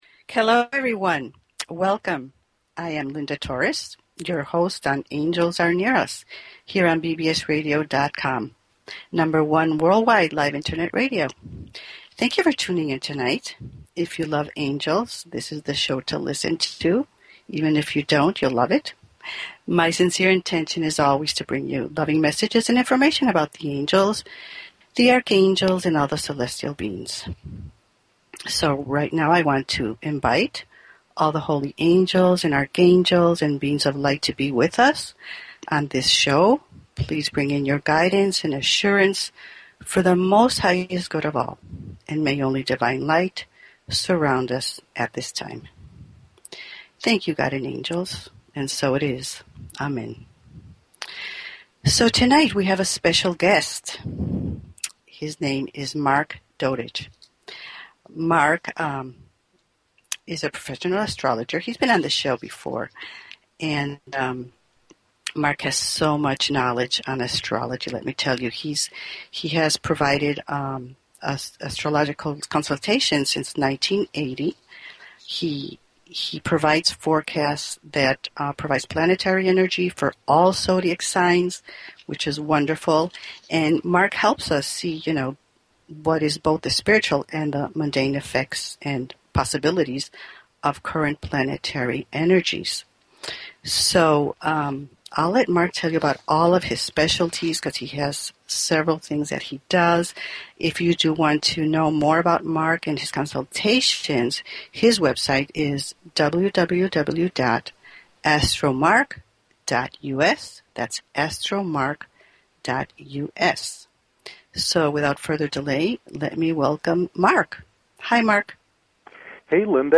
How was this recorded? The last 30 minutes of the show the phone lines will be open for questions and Angel readings.